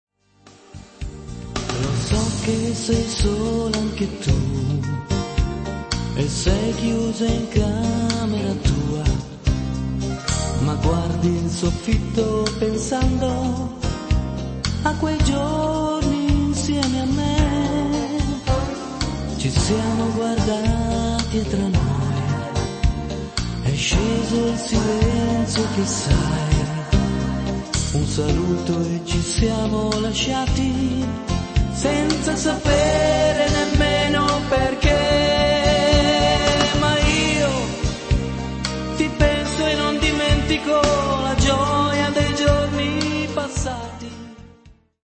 moderato-beguine